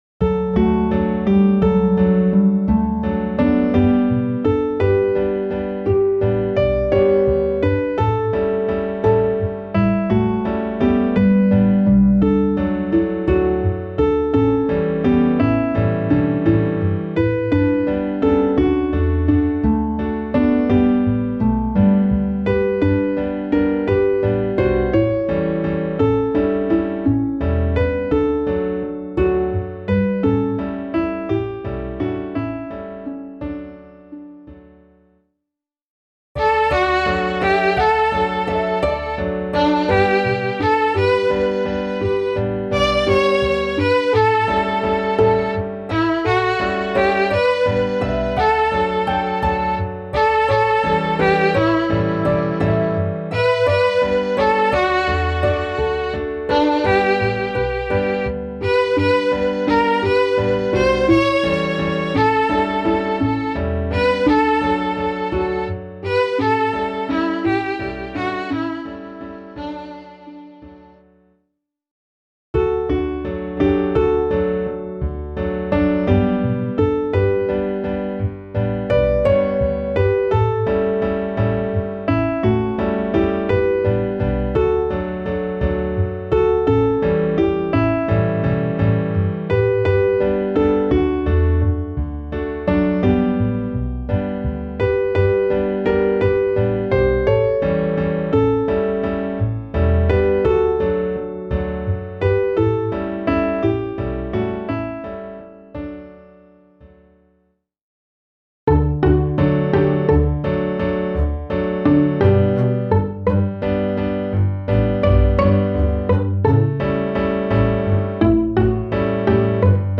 Die Hörprobe stammt von einer Studioaufnahme aus dem Jahr 2019.
Klaviersatz
midi_winternacht_klavier_320.mp3